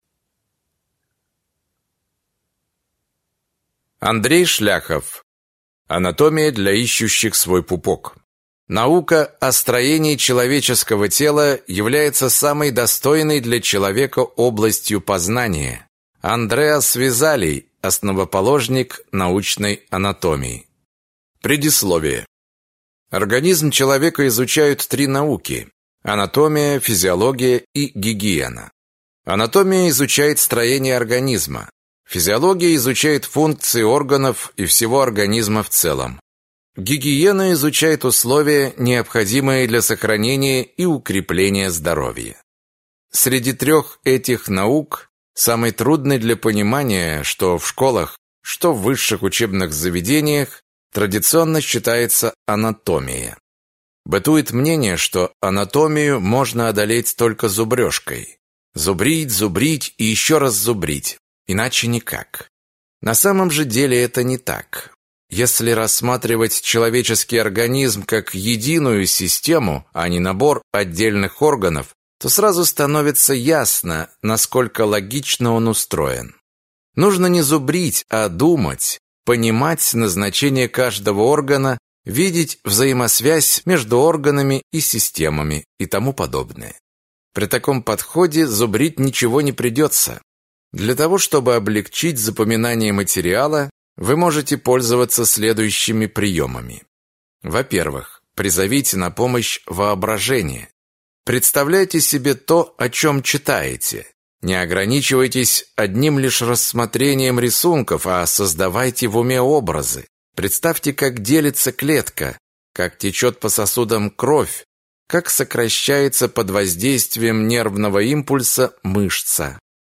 Aудиокнига Анатомия для ищущих свой пупок